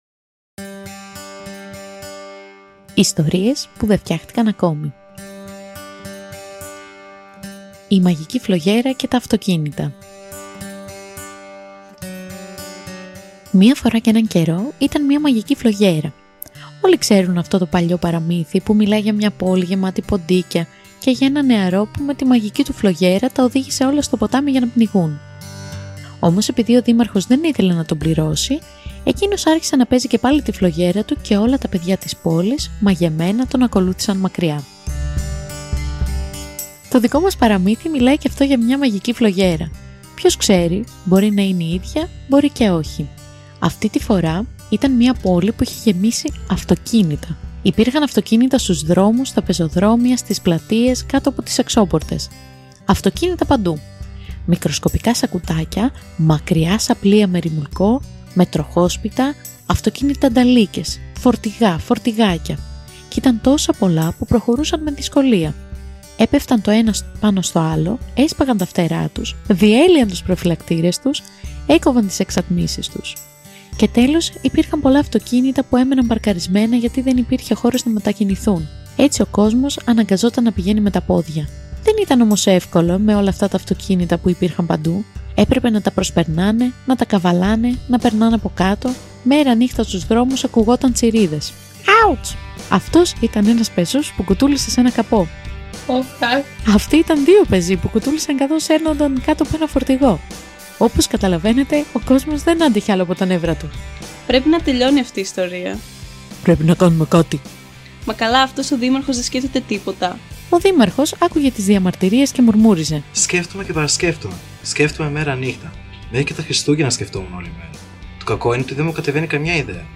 Κομμάτι: Green Day – Wake Me Up When September Ends (instrumental)